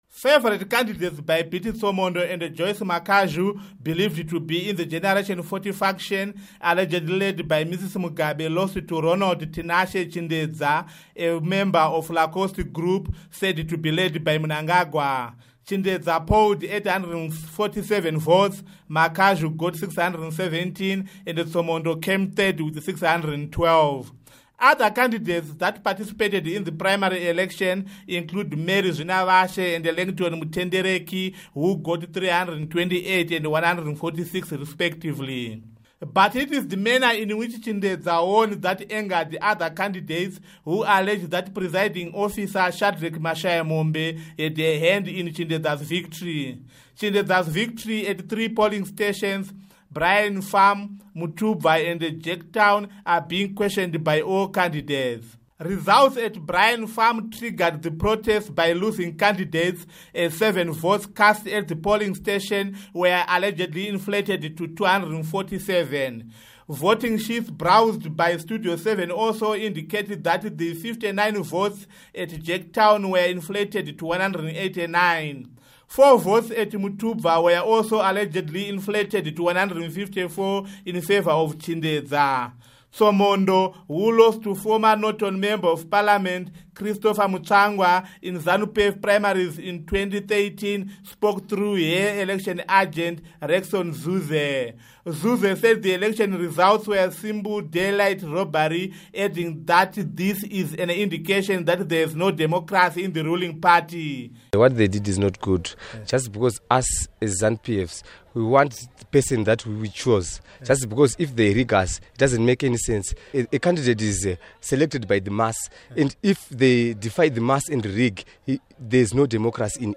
Report on Zanu PF Factionalism